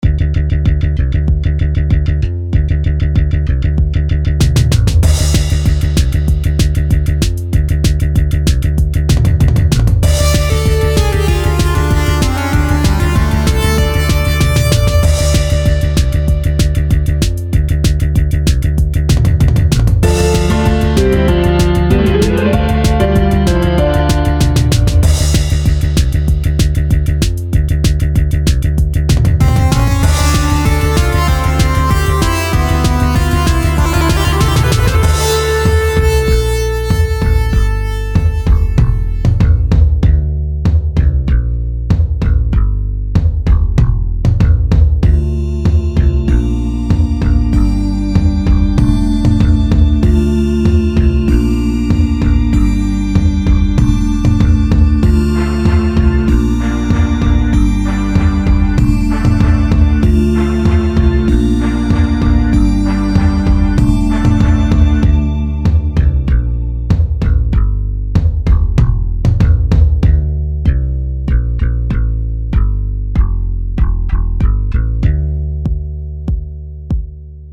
This is a battle theme I created for an RPG. It uses the Dorian mode in the key of D and is 192 BPM.
battle_theme_6.mp3